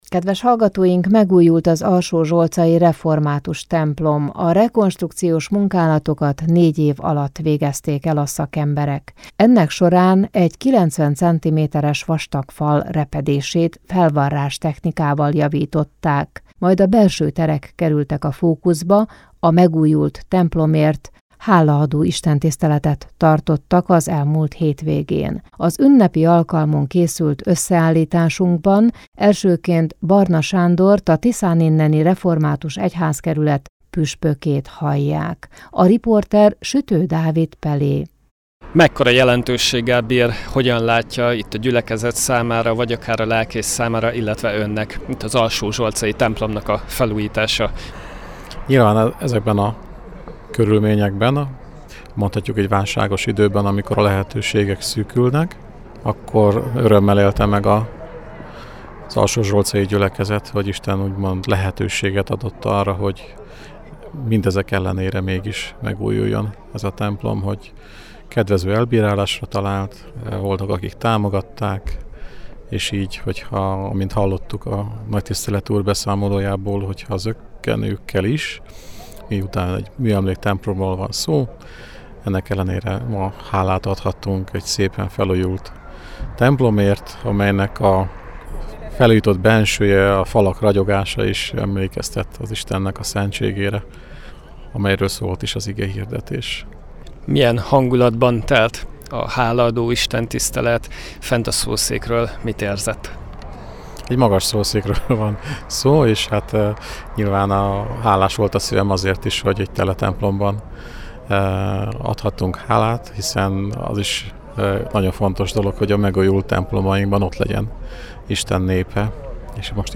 15_15_halaado_istentisztelet_alsozsolcan.mp3